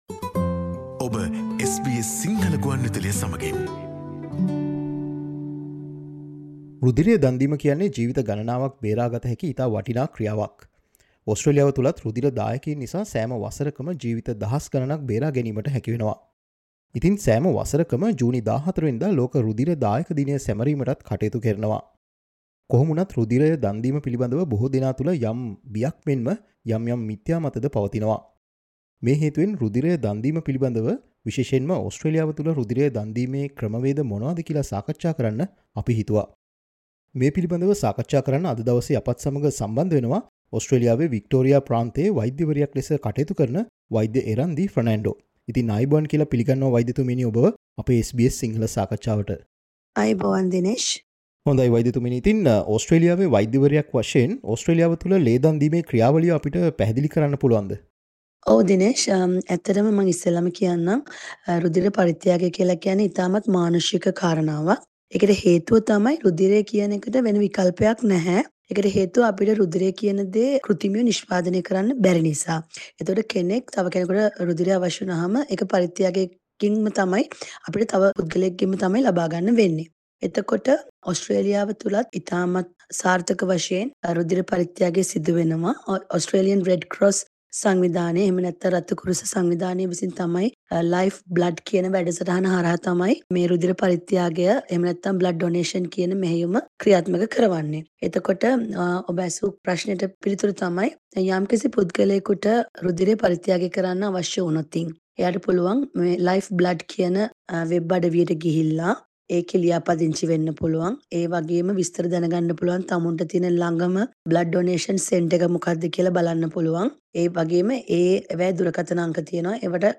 Listen to SBS Sinhala discussion about donating blood in Australia.